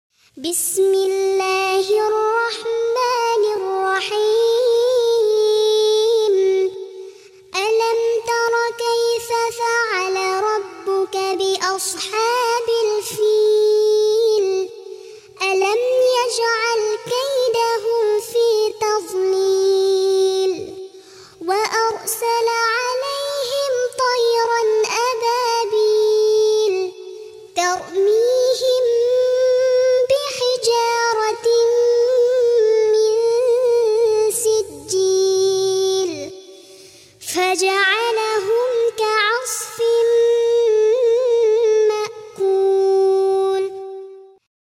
Murottal Anak